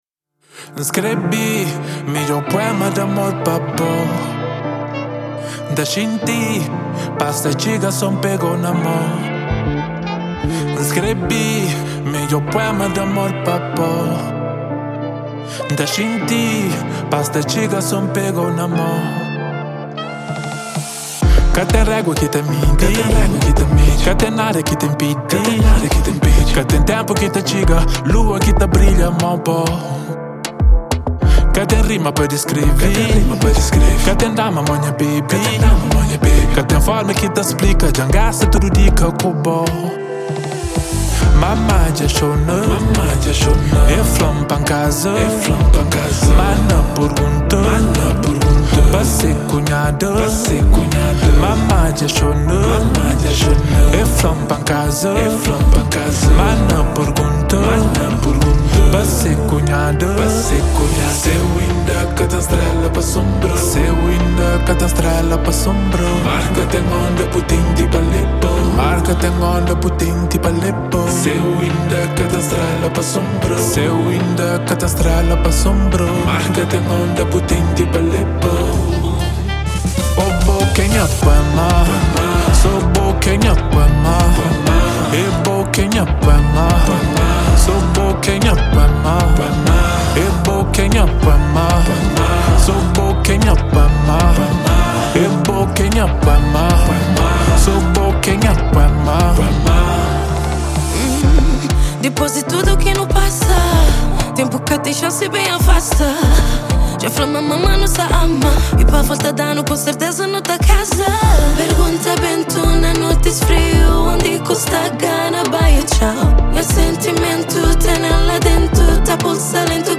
Gênero: Afro Beat